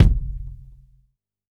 Index of /musicradar/Kit 14 - Acoustic
CYCdh_K5-Kick01.wav